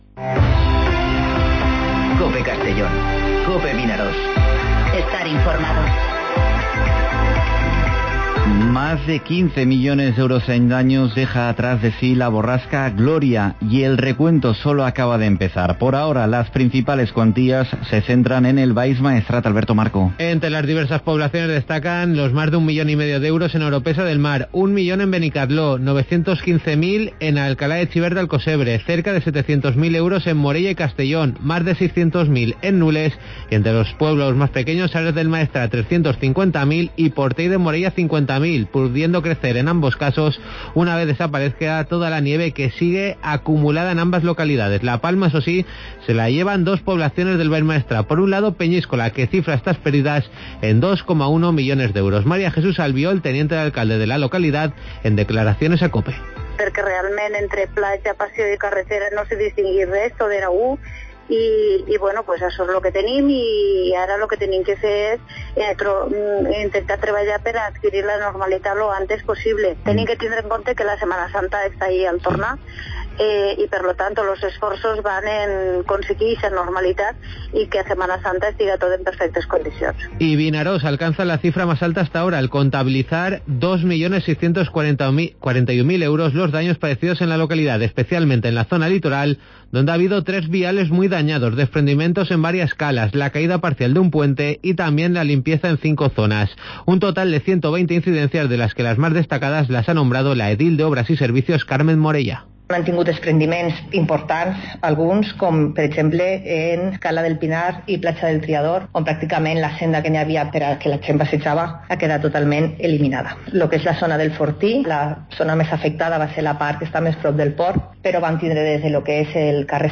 Informativo Mediodía COPE en Castellón (28/01/2020)